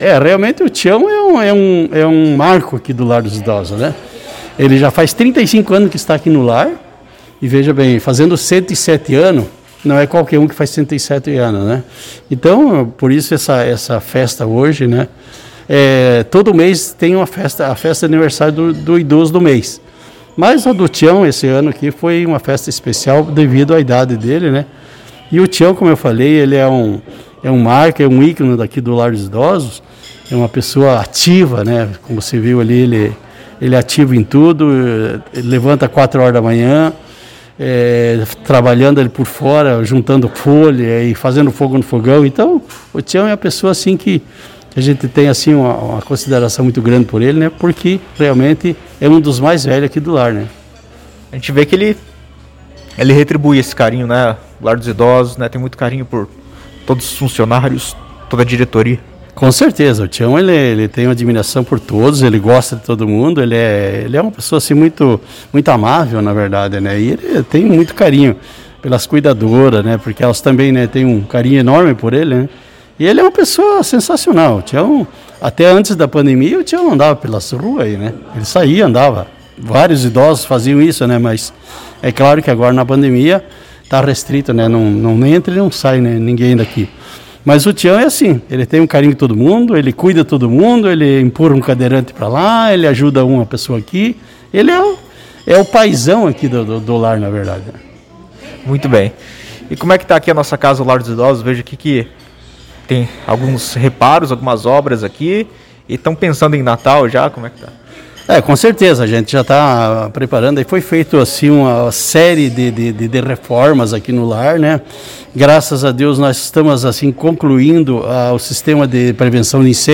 Em conversa com a reportagem da Rádio Jornal